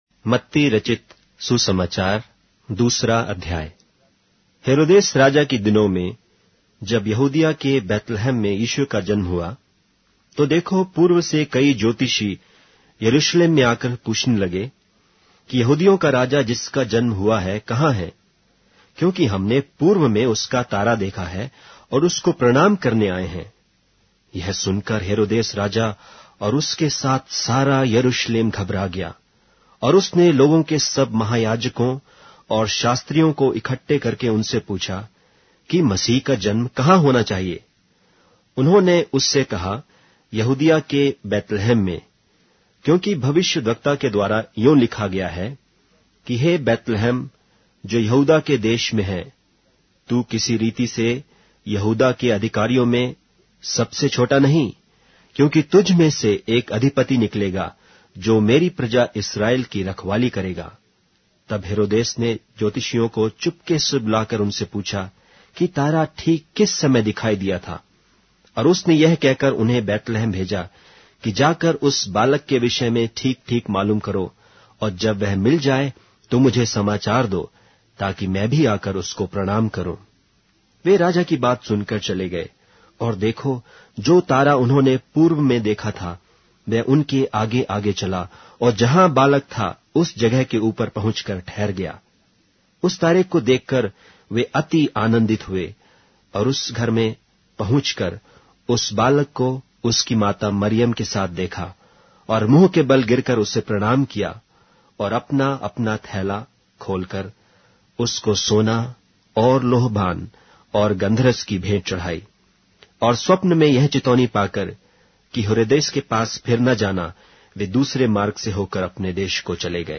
Hindi Audio Bible - Matthew 5 in Ervkn bible version